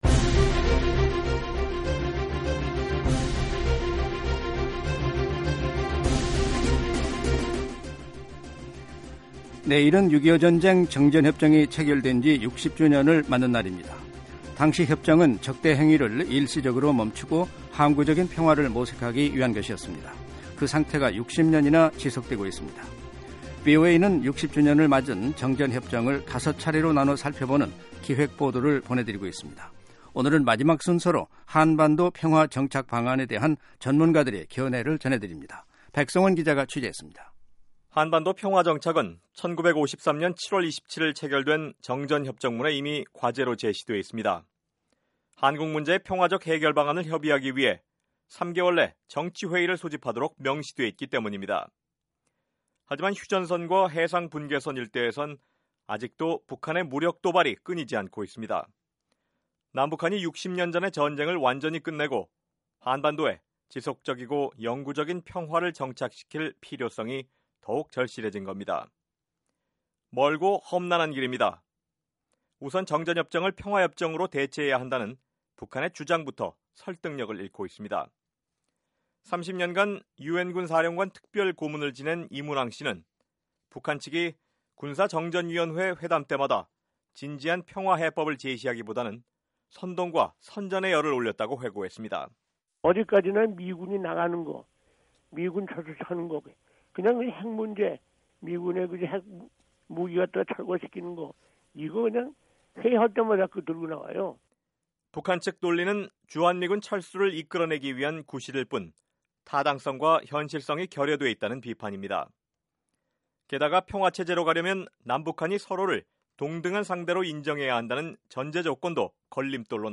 [기획보도: 한국전 정전 60주년] 5. 한반도 평화 정착, 전문가 제언